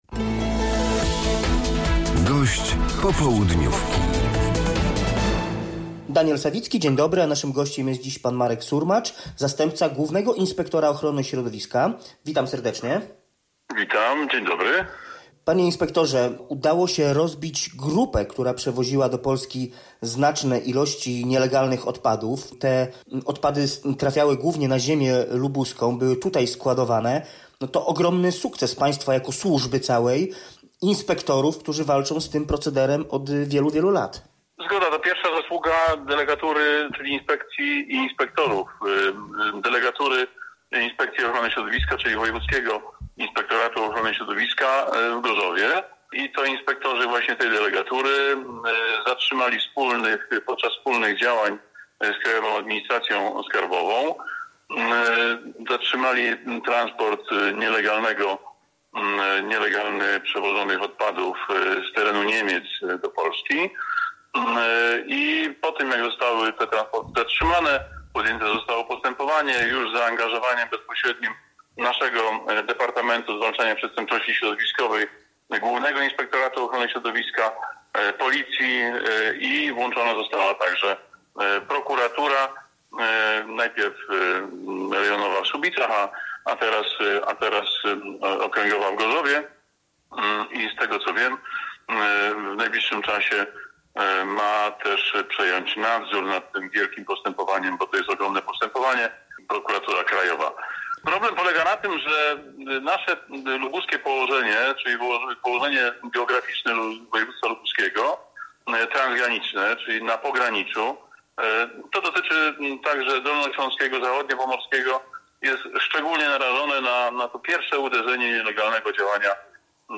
Z zastępcą Głównego Inspektora Środowiska rozmawiał